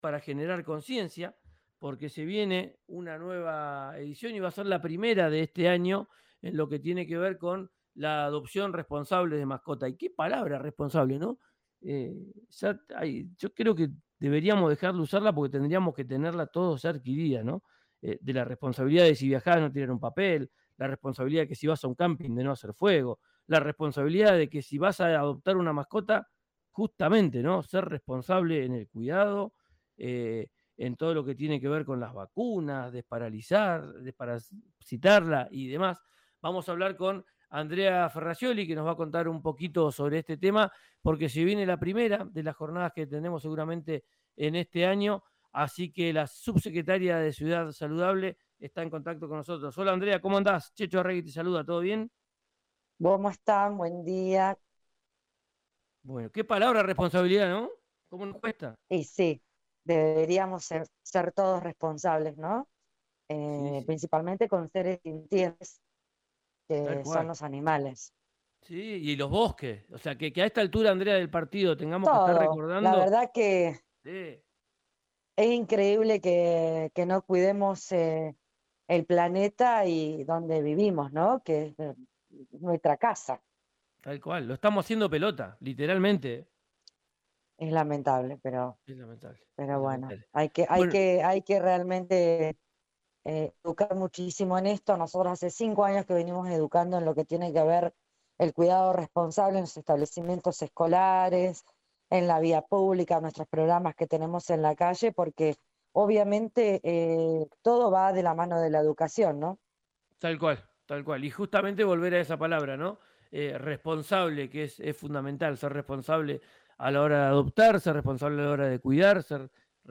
Escuchá la nota con la subsecretaria de Ciudad Saludable, Andrea Ferracioli por RÍO NEGRO RADIO